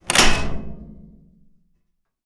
hatch_close.wav